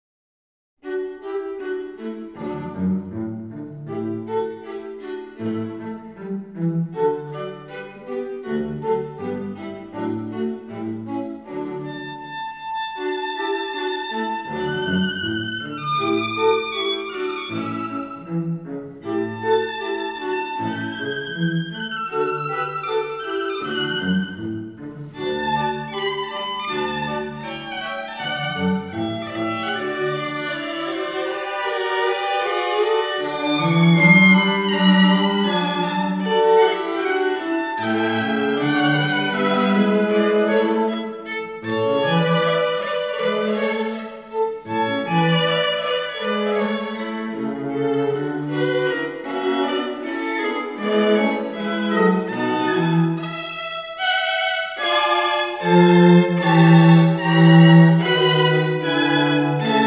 Processed version